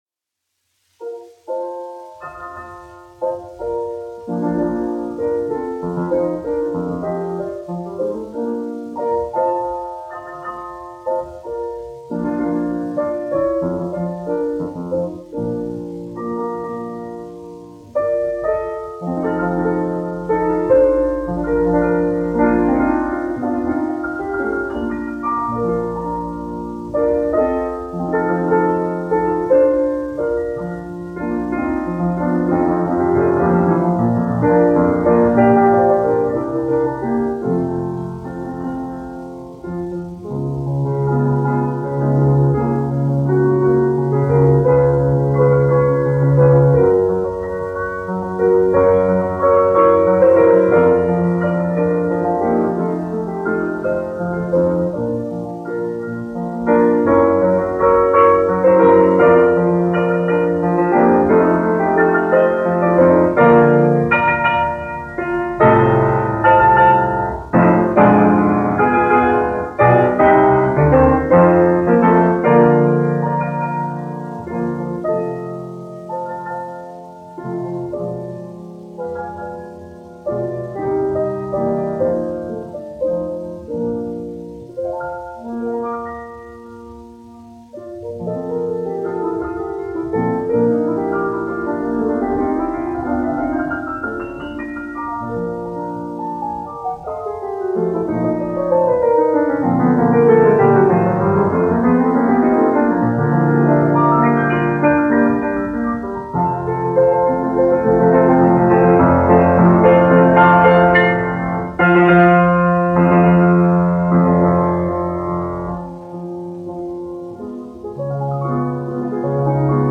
Franz Liszt, 1811-1886, aranžētājs
1 skpl. : analogs, 78 apgr/min, mono ; 25 cm
Klavieru mūzika, aranžējumi
Skaņuplate